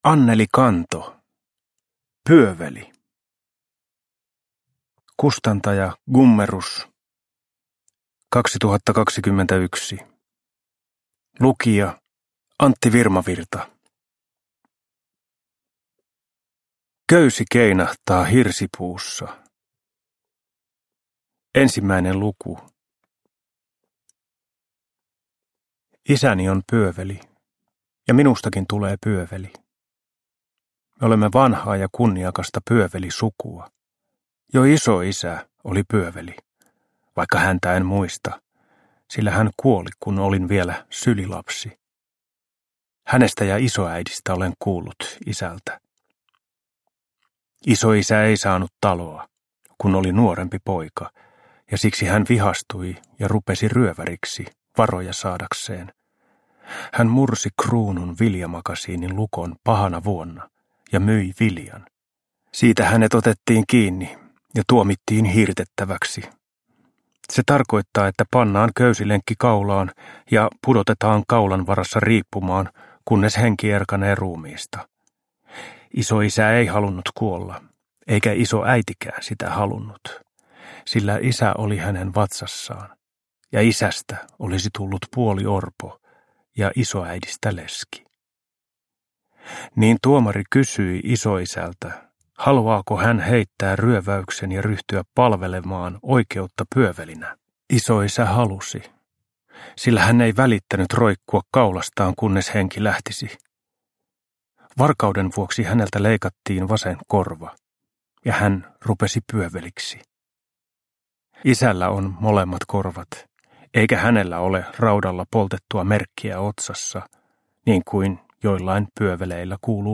Pyöveli – Ljudbok – Laddas ner